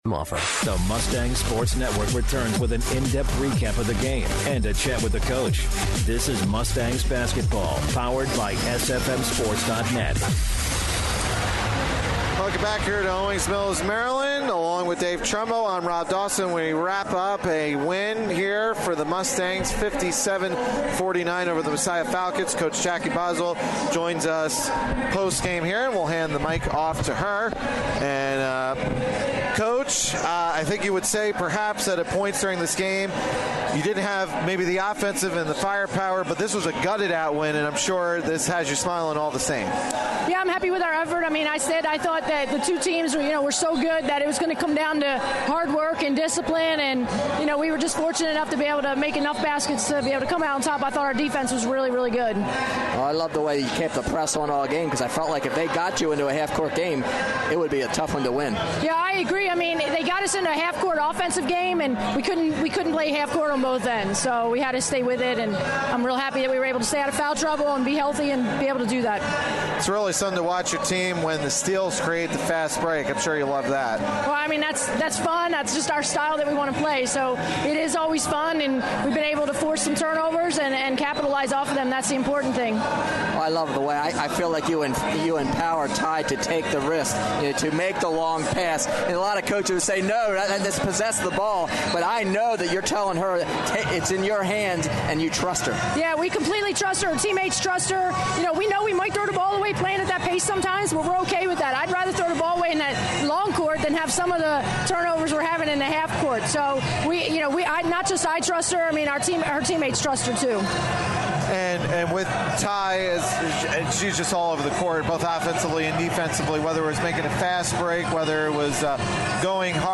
1/7/15: Stevenson Women's Basketball Post Game